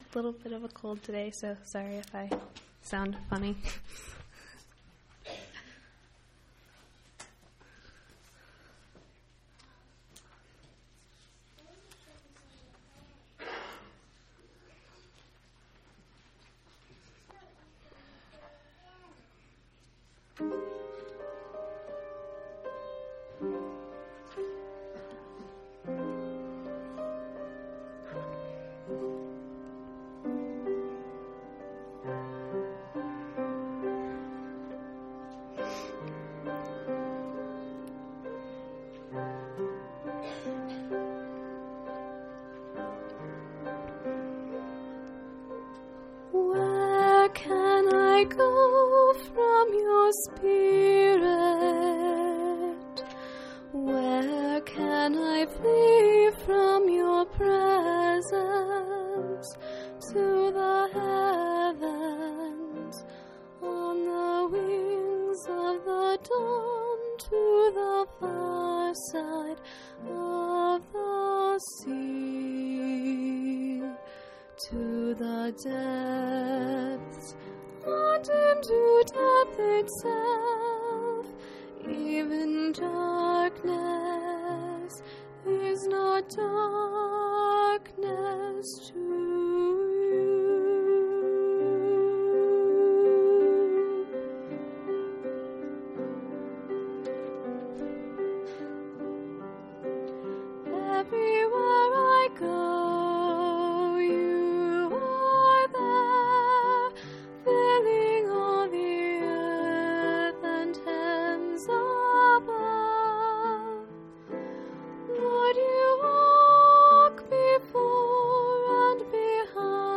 1/29/2006 Location: Phoenix Local Event